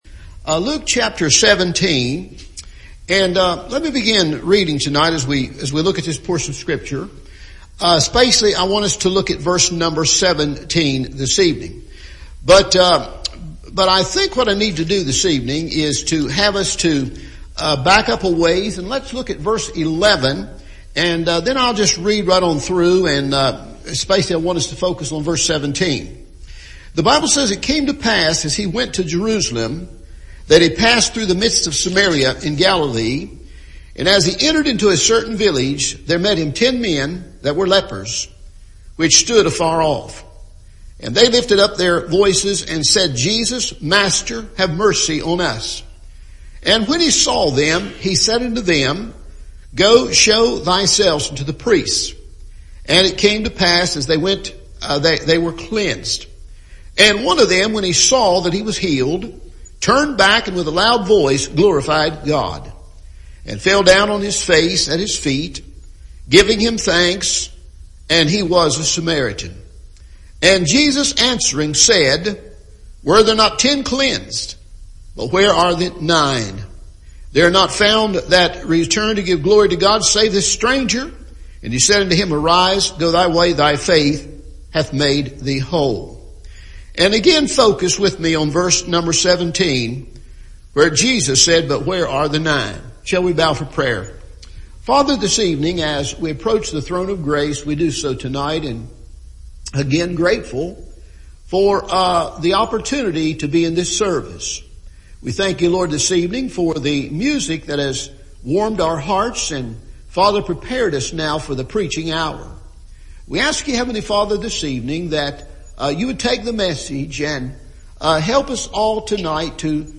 Missing in Action – Evening Service